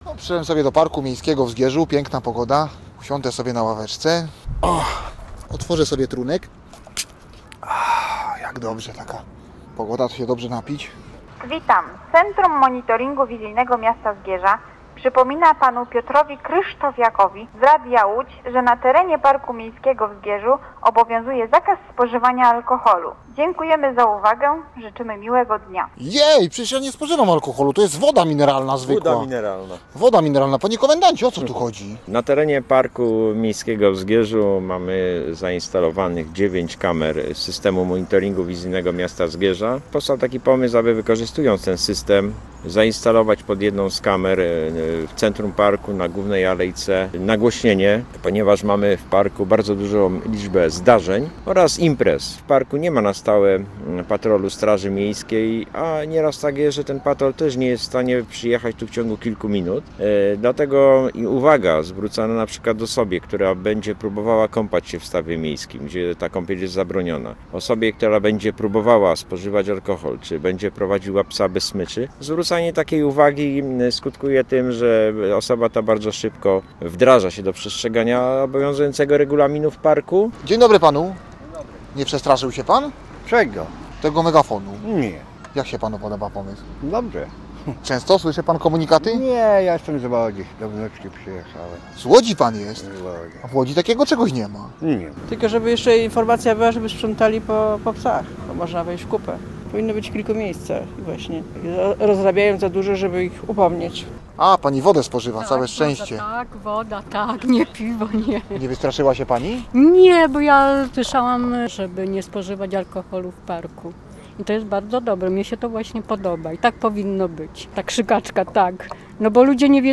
Posłuchaj relacji i dowiedz się więcej: Nazwa Plik Autor Megafon w zgierskim parku audio (m4a) audio (oga) ZDJĘCIA, NAGRANIA WIDEO, WIĘCEJ INFORMACJI Z ŁODZI I REGIONU ZNAJDZIESZ W DZIALE “WIADOMOŚCI”.